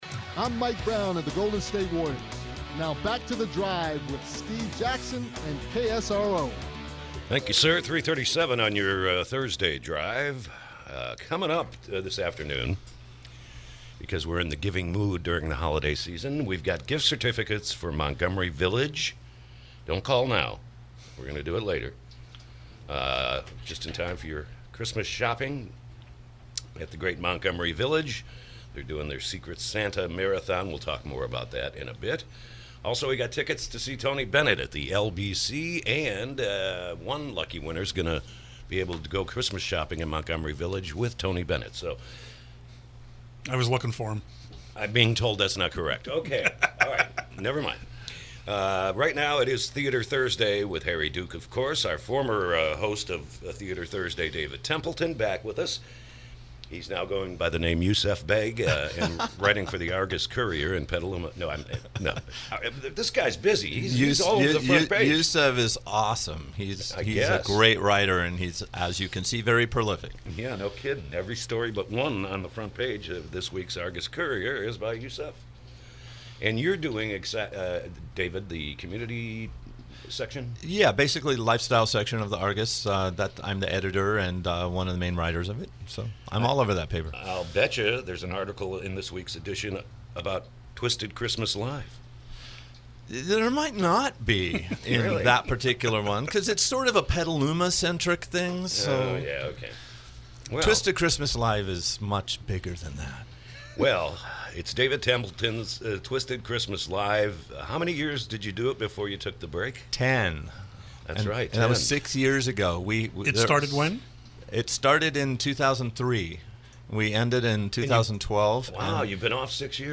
KSRO Interview